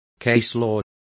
Also find out how jurisprudencia is pronounced correctly.